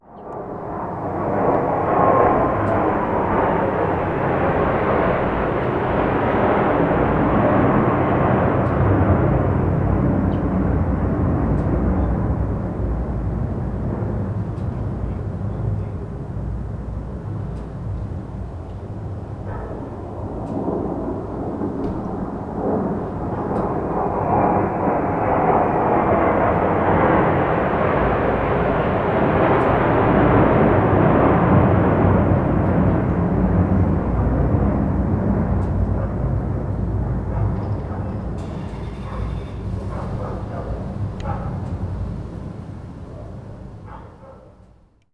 • F16 Fighter Jet passing by dog barking and engine sputtering.wav
Recorded with a Tascam DR40 while few F 16 Fighters fly over our small apartment building.
F16_Fighter_Jet_passing_by_dog_barking_and_engine_sputtering_FXR.wav